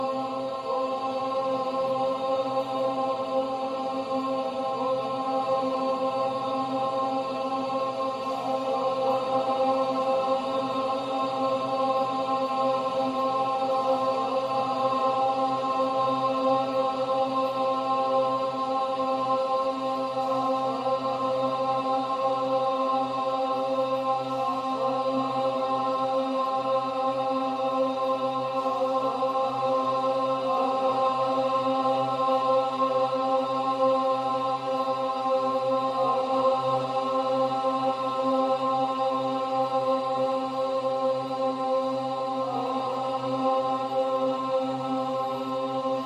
Acapella                    Durée 15:38